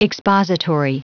Prononciation du mot expository en anglais (fichier audio)
Prononciation du mot : expository